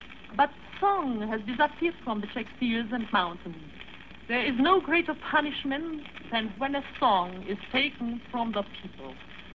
I Saw My Country Die: A Radio Interview with Jarmila Novotna